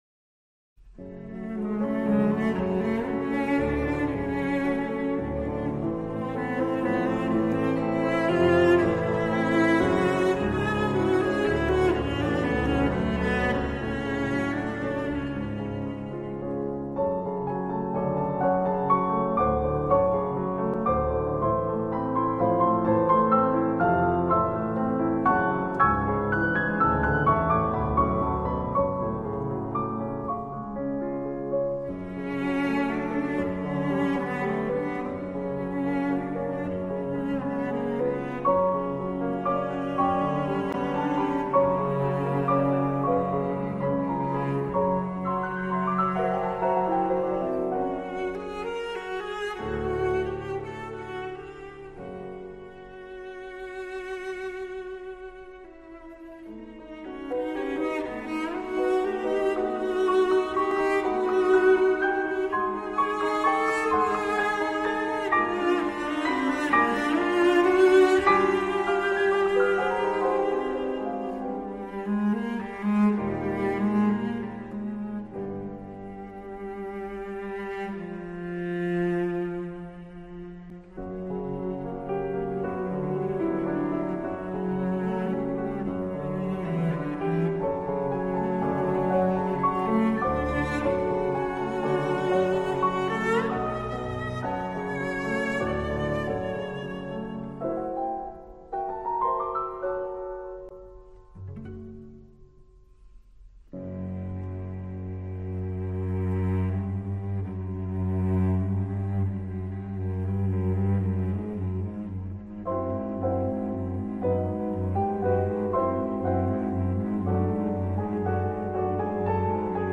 Soundbite Movt 1
Sonata for Violoncello and Piano in a minor